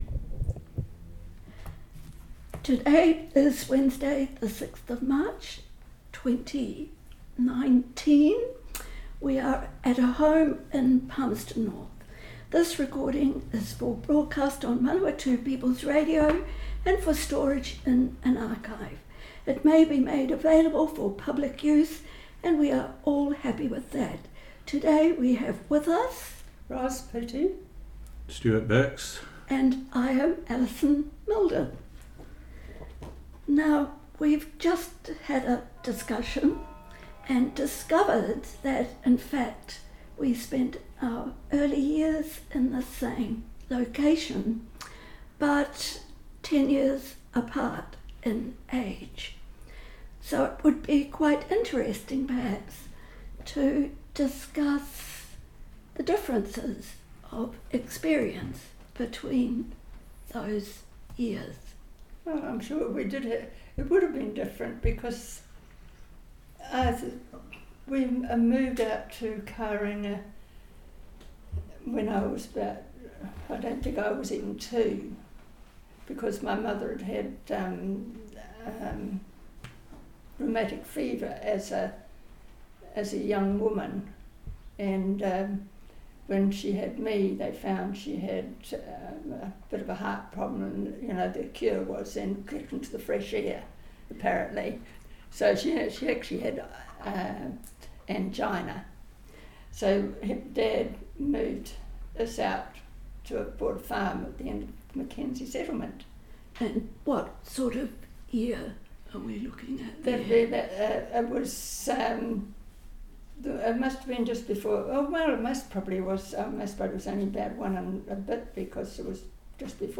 Manawatū Conversations More Info → Description Broadcast on Manawatū People's Radio, 4 June, 2019.